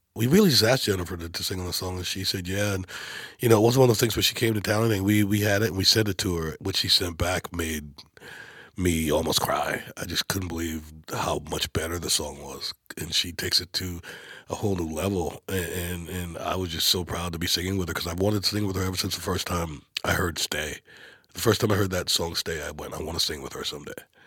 Darius Rucker talks about getting Jennifer Nettles on his new song, "Never Been Over."
Darius-Rucker-getting-Jennifer-on-Never-Been-Over.mp3